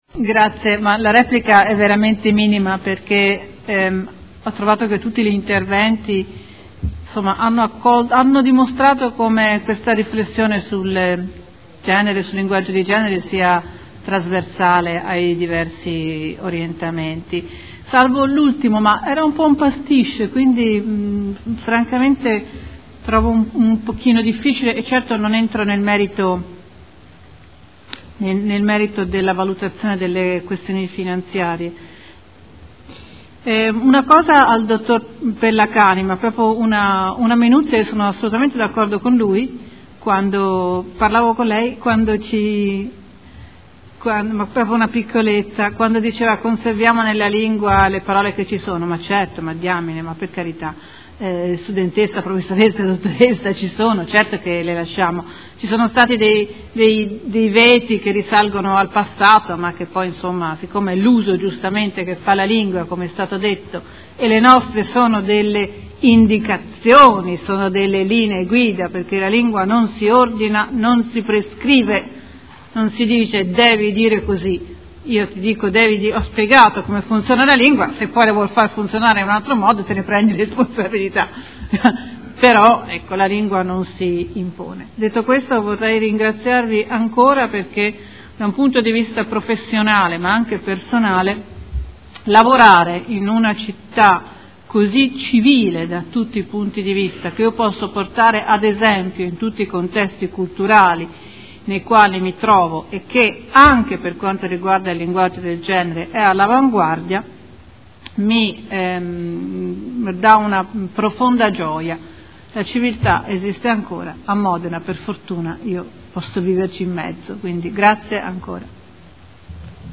Seduta del 18/06/2015.